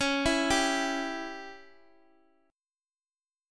Csharp-Diminished-Csharp-x,x,x,6,5,3-8-down-Guitar-Standard-1.m4a